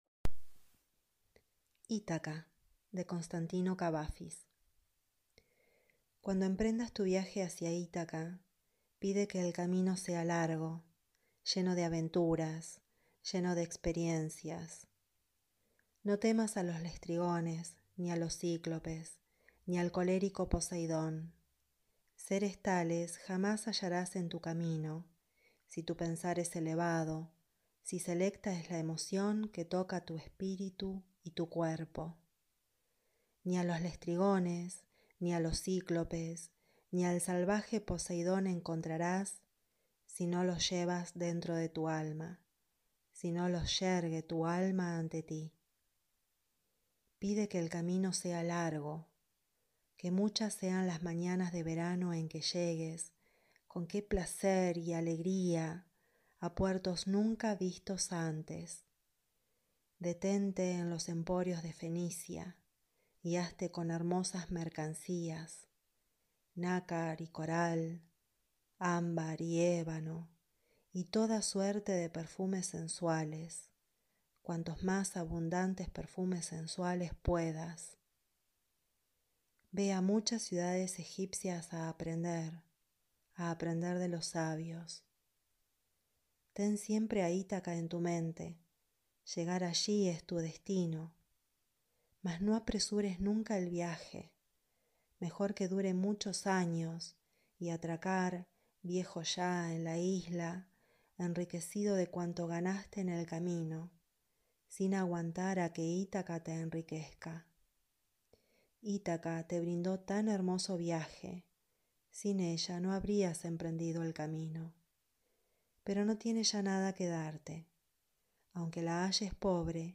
leído